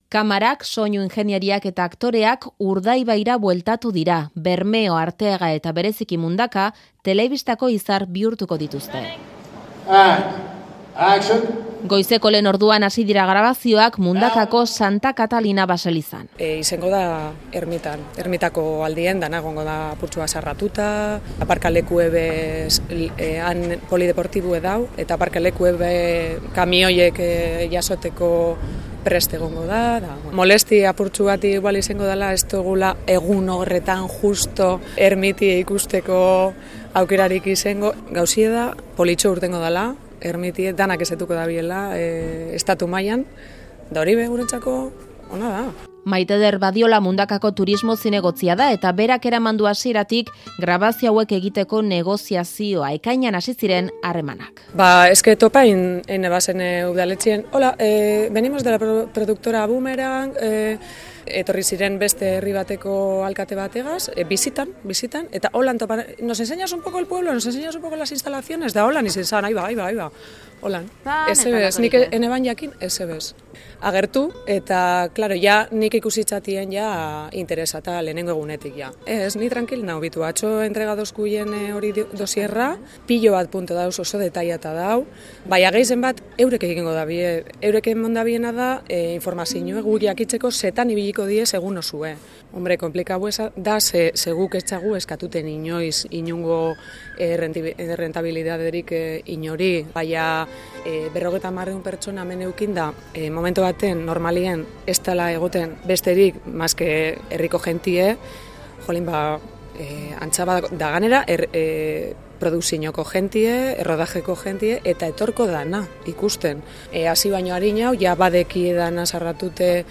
Euskadi Irratiko Faktoria inguru horretara hurbildu da.